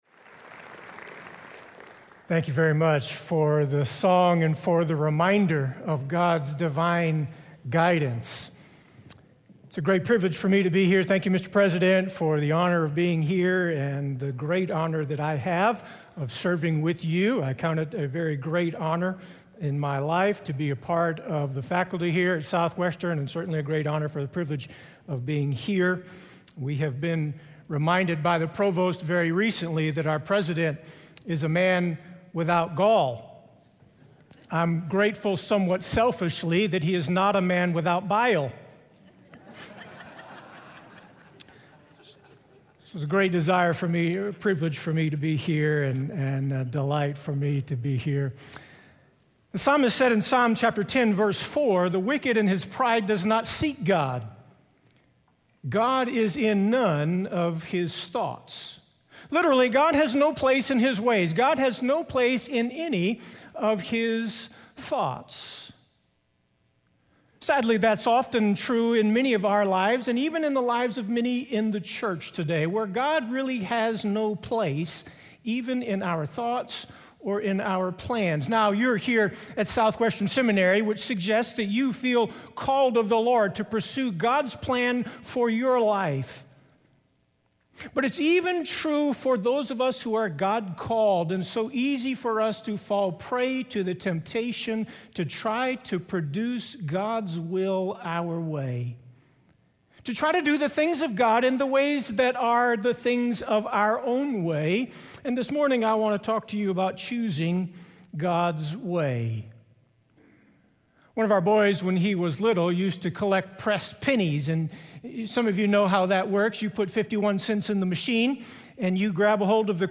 speaking on Psalm 119:25-32 in SWBTS Chapel